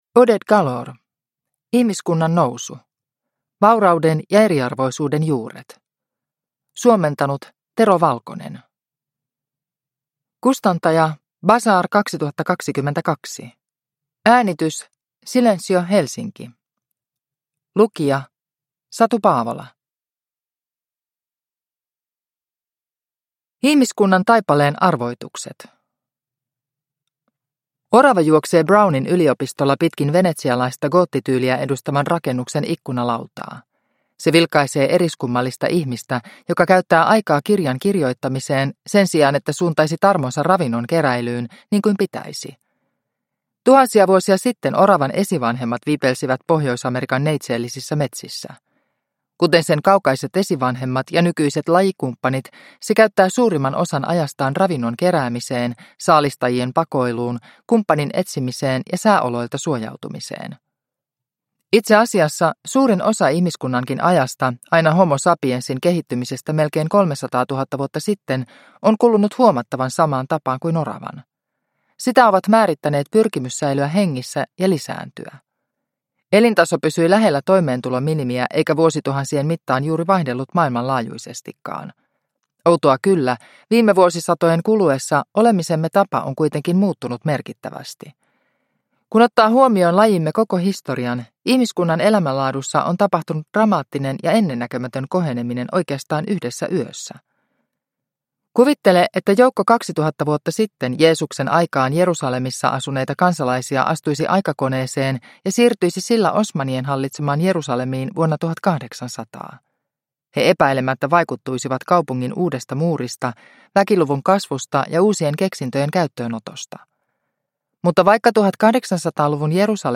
Ihmiskunnan nousu – Ljudbok – Laddas ner